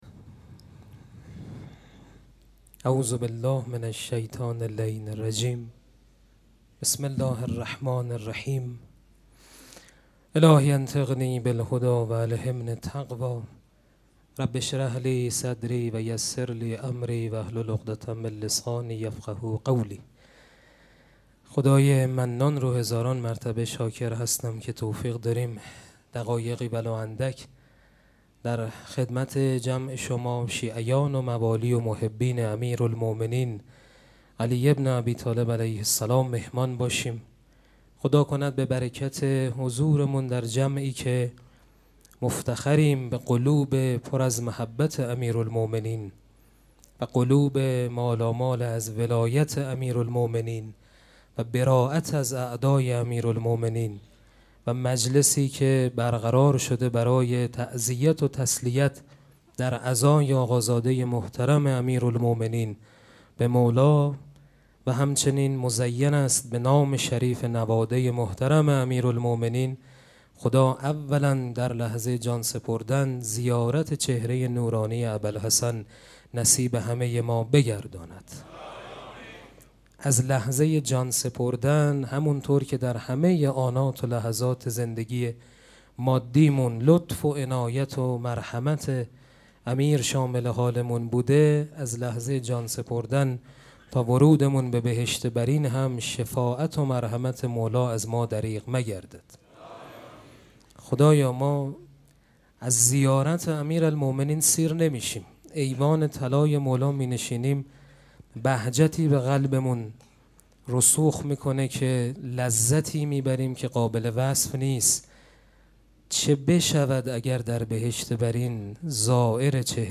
سخنرانی
مراسم عزاداری شب سوم محرم الحرام ۱۴۴۷ شنبه ۷ تیر ۱۴۰۴ | ۲ محرم‌الحرام ۱۴۴۷ هیئت ریحانه الحسین سلام الله علیها